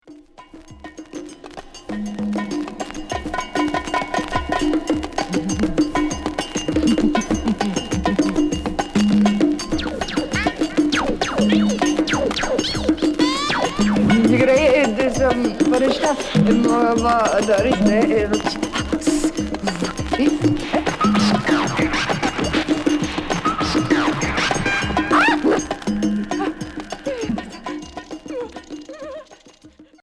Psychédélique